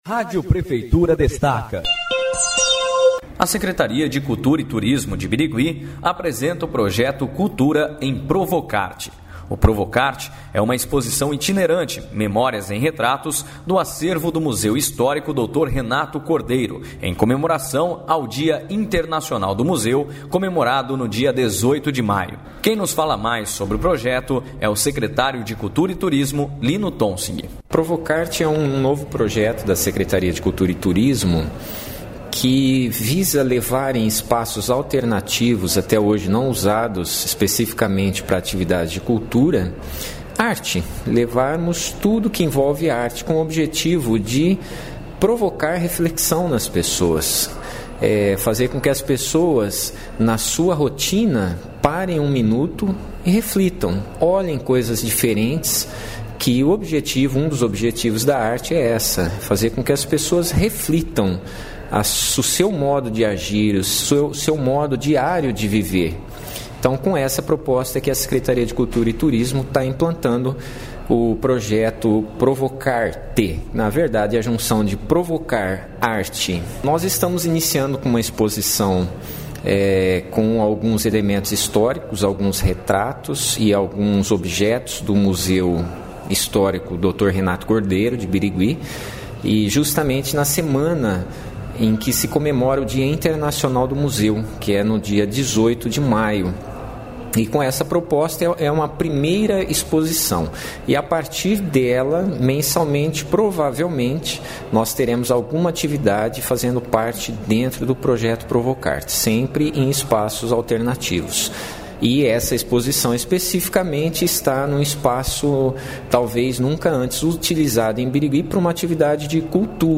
A Rádio Prefeitura falou com o secretário de Cultura e Turismo, Lino Tonsig, que explicou como funcionará o projeto ProvocArte.